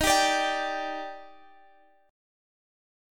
Listen to EbM7 strummed